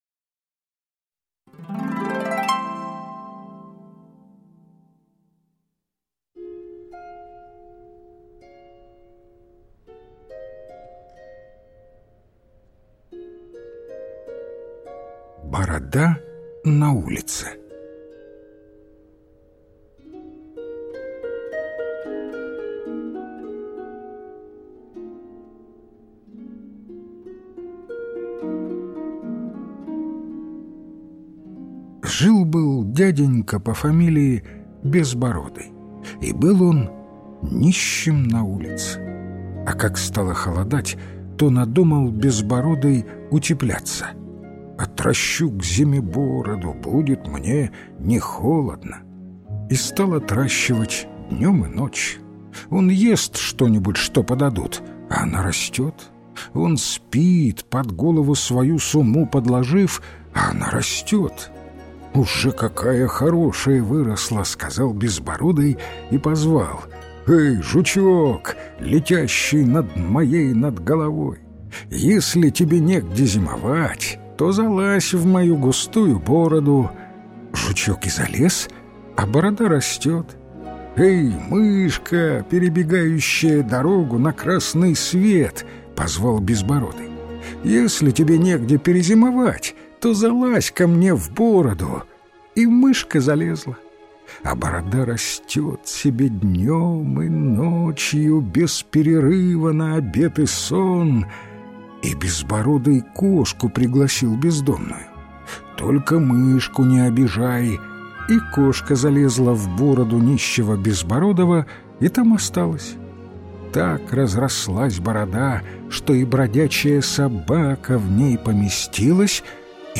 Аудиокнига Дыхания и другие сказки | Библиотека аудиокниг